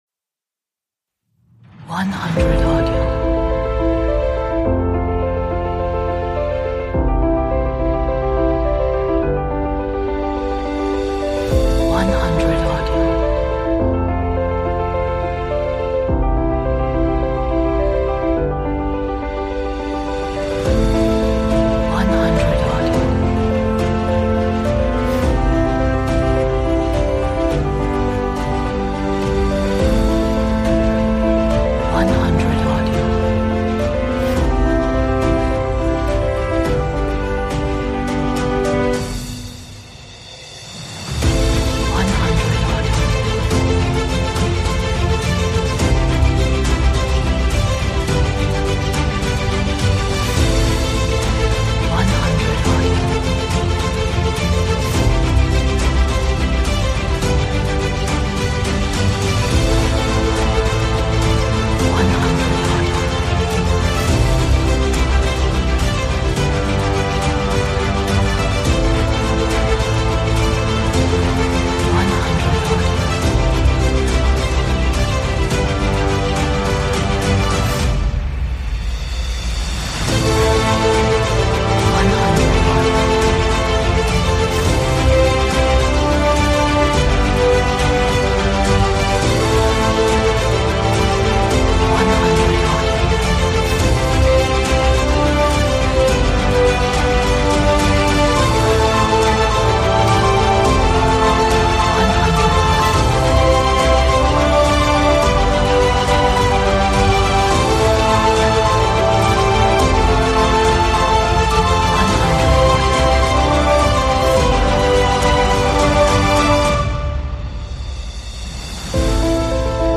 Atmospheric, dramatic, epic, energetic,